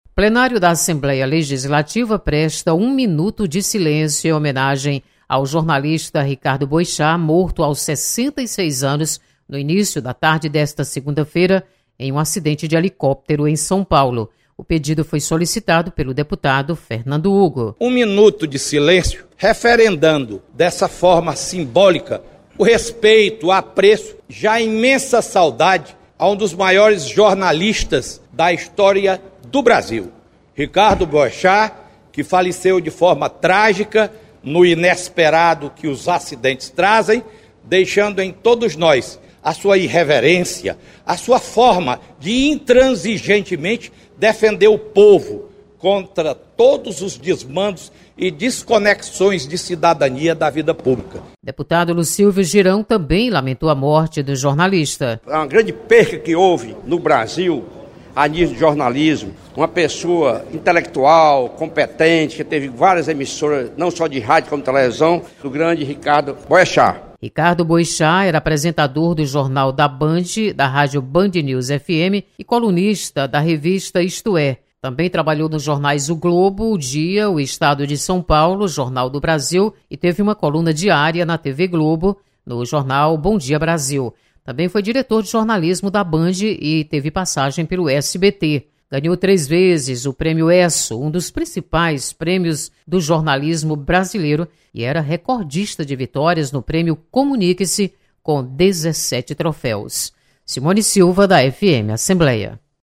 Plenário da Assembleia presta um minuto de silêncio em homenagem ao jornalista Ricardo Boechat. Repórter